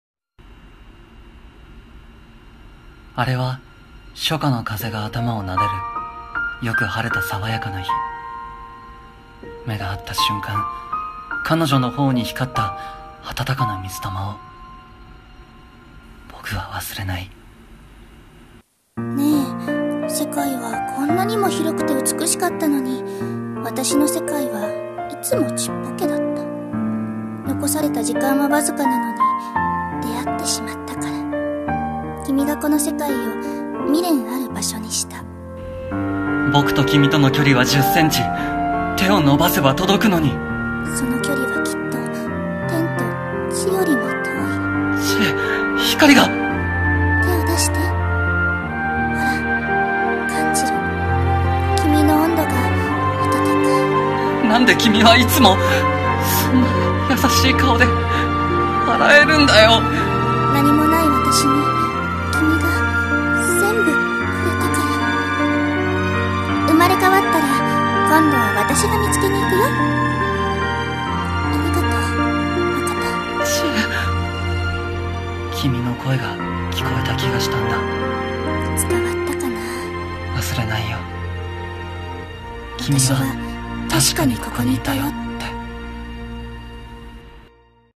【映画予告風声劇】